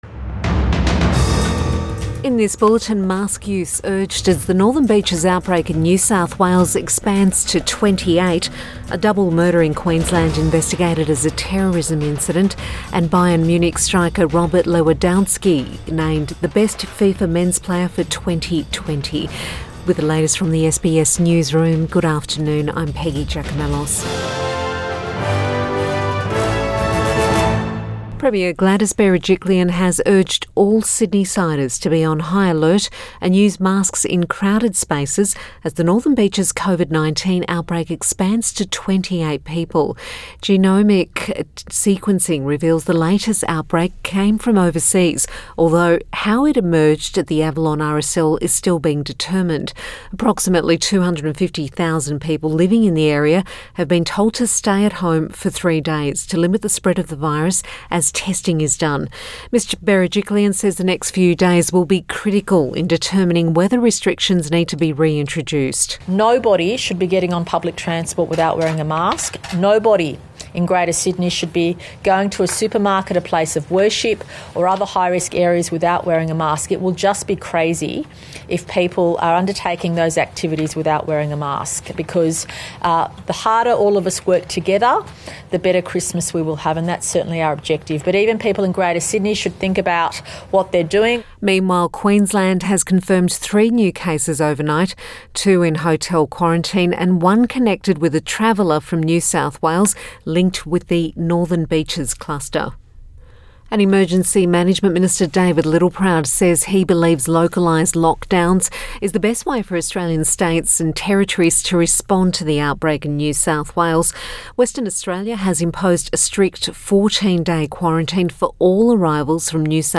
Midday bulletin 18 December 2020